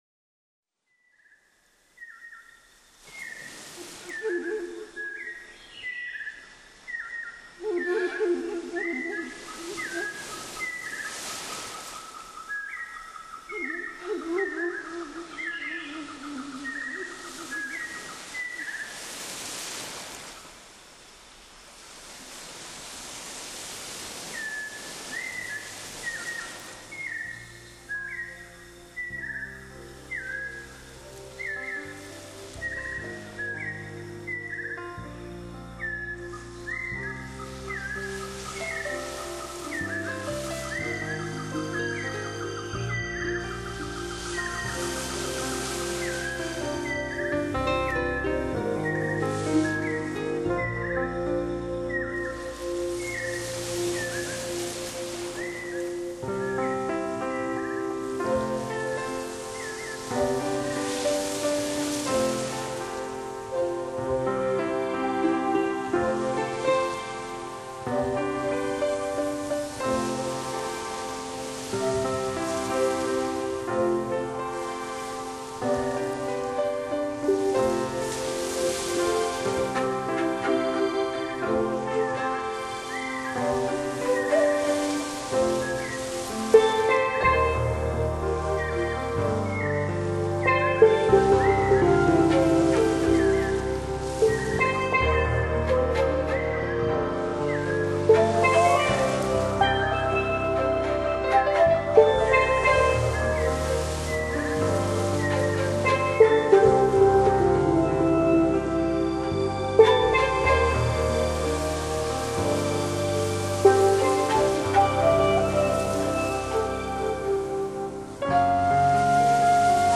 类别：自然音乐
，运用了先进的录音技术，把大海中孤岛上所能听到的自然之声和人类的纯净音乐，辅以天使般的女声吟唱，产生了非常动人的效果。